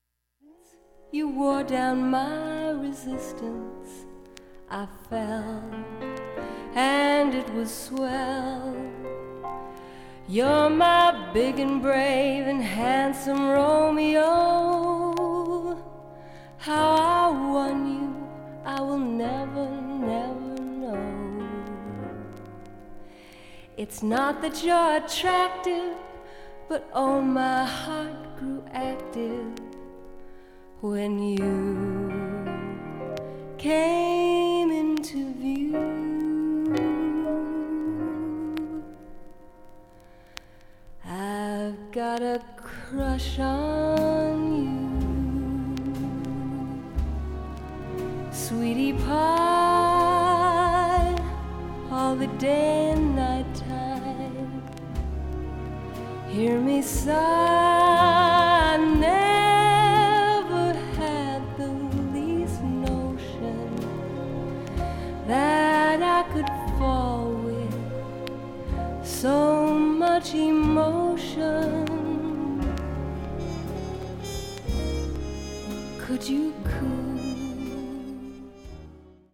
４回までのかすかなプツが１箇所 ３回までのかすかなプツが２箇所 単発のかすかなプツが７箇所